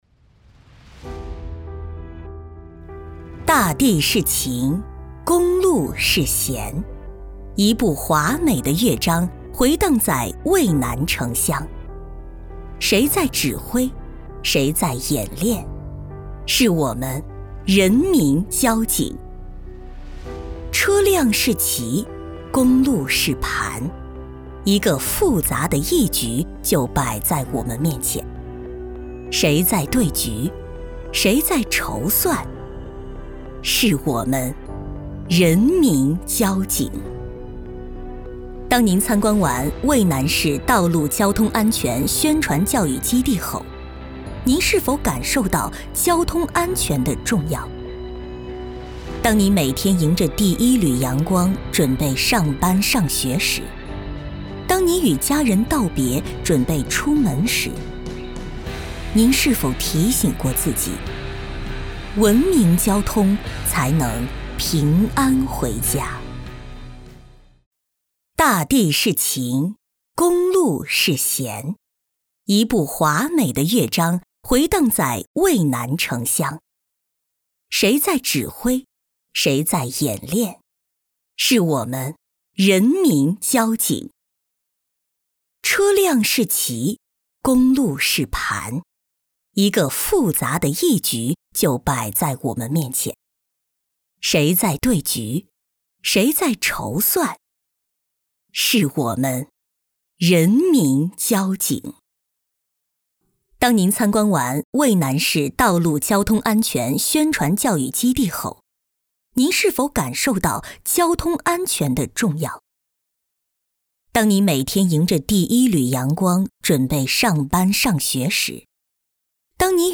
标签： 大气
配音风格： 大气 轻快 稳重 活力 磁性 温暖 温柔
政府专题配音